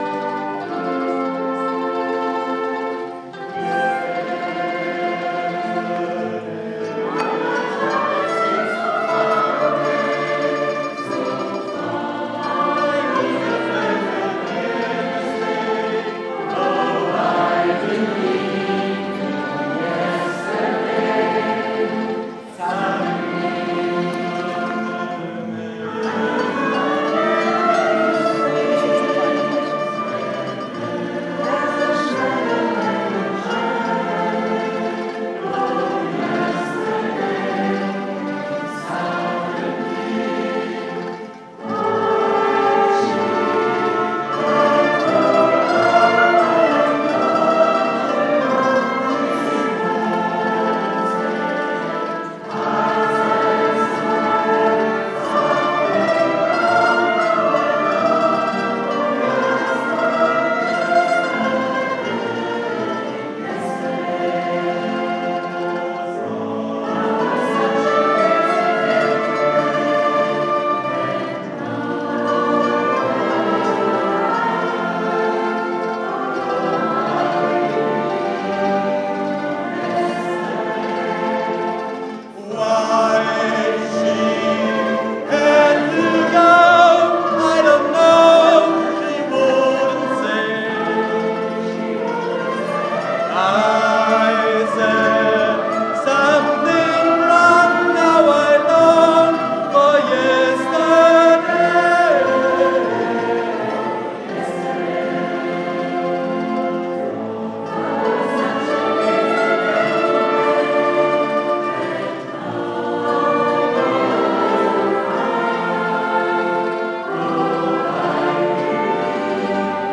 Konzert 9 Okt.2011
Wir hören den Titel Yesterday Mandolinenorchester Eltern - Lehrerchor